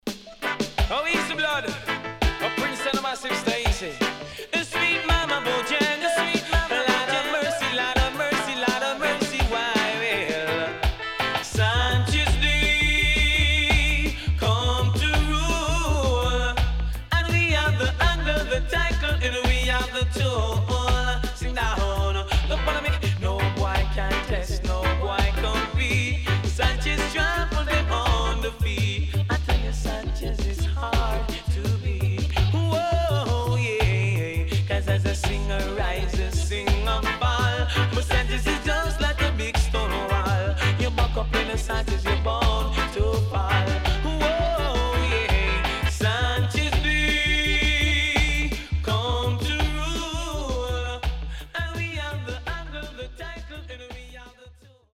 SIDE A:うすいこまかい傷ありますがノイズあまり目立ちません。